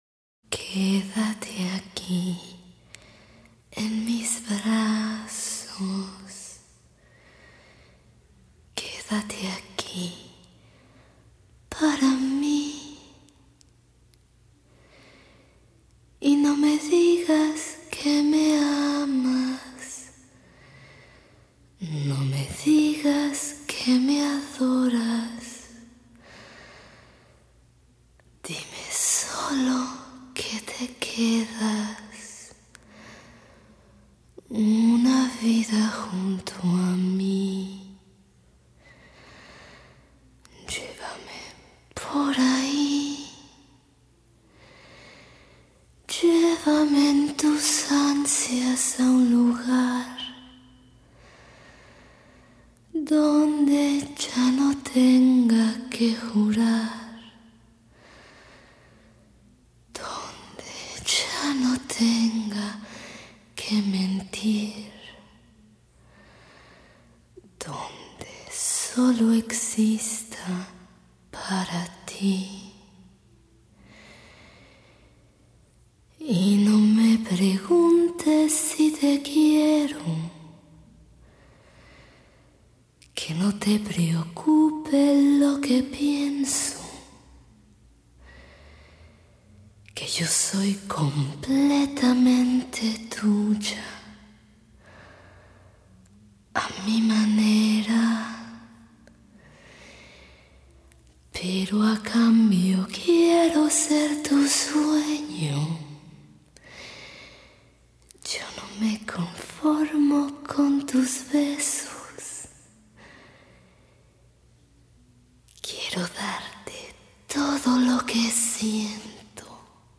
érzéki hangon elsusogja